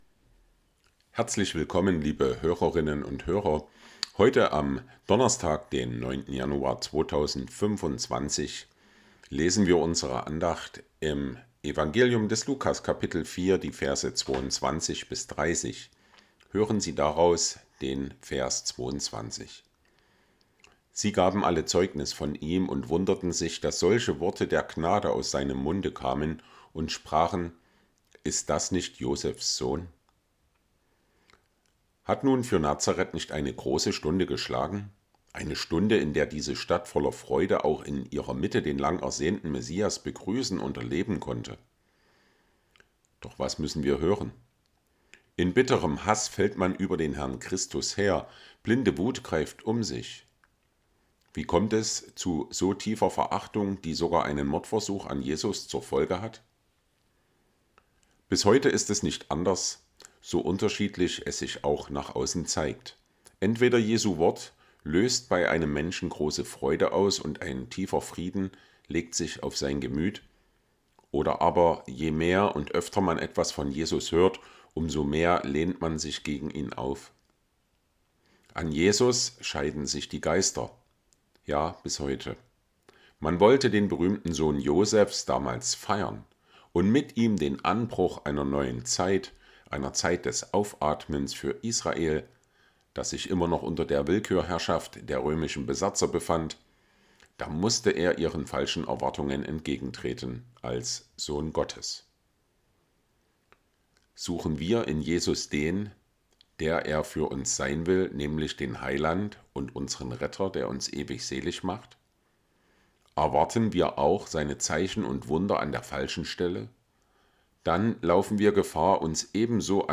Tägliche Andachten aus dem Andachtsheft der Ev.-Luth. Freikirche